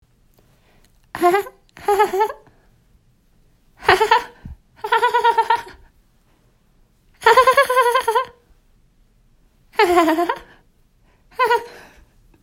hahahaha.m4a
cartoon funny haha laugh laughing woman sound effect free sound royalty free Funny